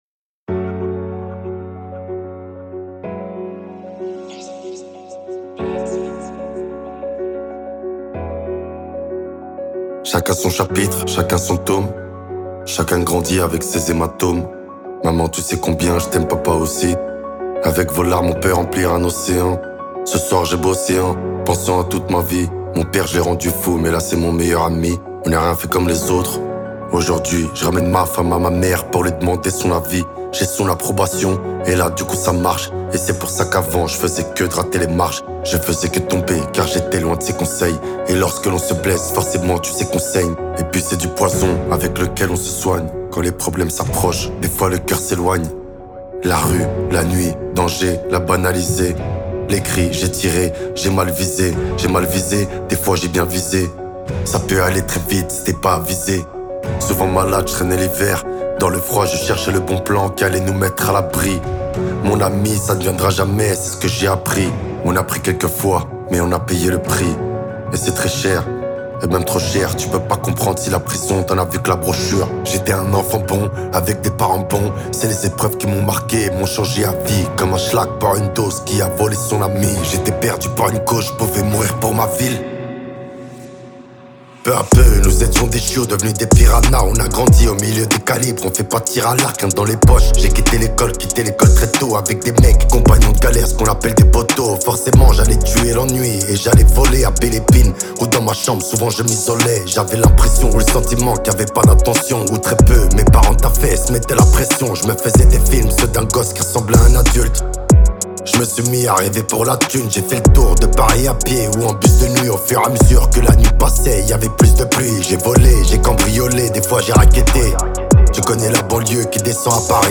0/100 Genres : raï Écouter sur Spotify